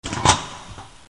Schiocco metallico
Rumore metallico tipo accensione moto, o suono simile.